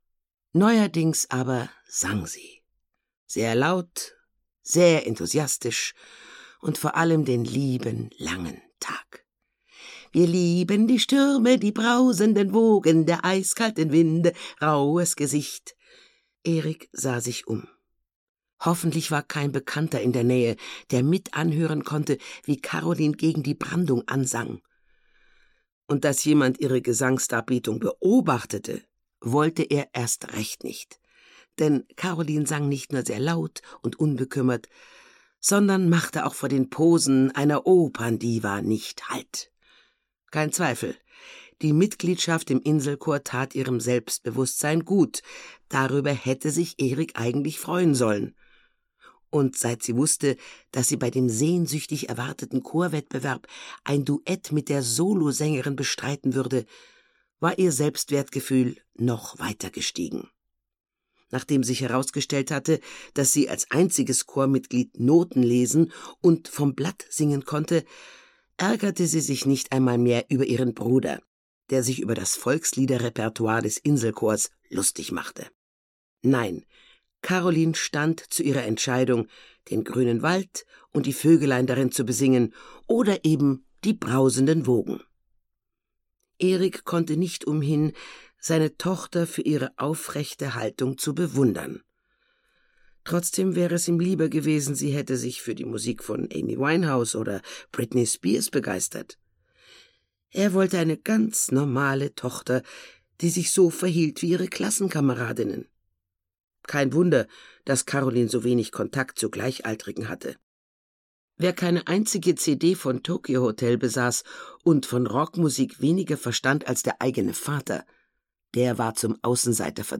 Tod im Dünengras (Mamma Carlotta 3) - Gisa Pauly - Hörbuch